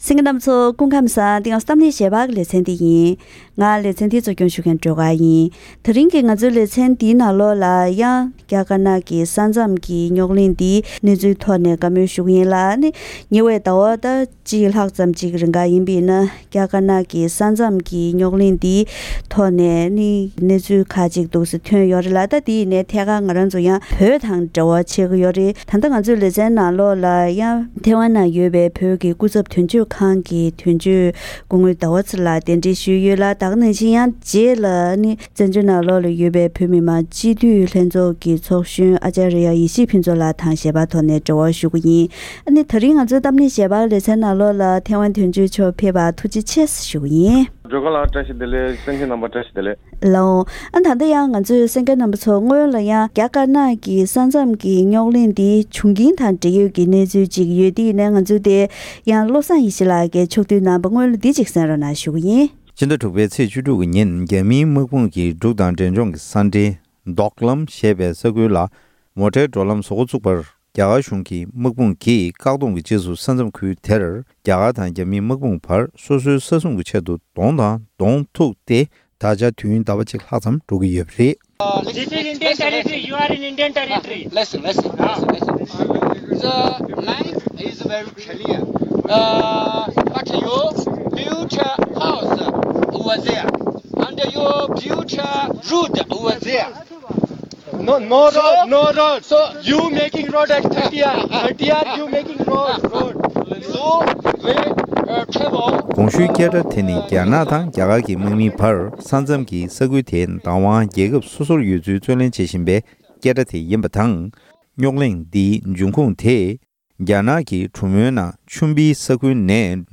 ༄༅། །ཐེངས་འདིའི་གཏམ་གླེང་ཞལ་པར་ལེ་ཚན་ནང་རྒྱ་དཀར་ནག་གི་ས་མཚམས་ཟེར་བའི་ཁུལ་དུ་རྙོག་གླེང་བྱུང་བཞིན་ཡོད་པའི་ཐད་ཡུལ་གཉིས་ཀྱི་ངོས་འཛིན་བྱེད་སྟངས་དང་ལོ་རྒྱུས་ཐོག་བོད་ཀྱི་ས་ཁུལ་ཡིན་པས། དེ་སྔ་བོད་གཞུང་གིས་ས་མཚམས་ཁུལ་དབྱེ་སྟངས་སོགས་འབྲེལ་ཡོད་གནས་ཚུལ་ཐོག་བཀའ་མོལ་ཞུས་པ་ཞིག་གསན་རོགས་གནང་།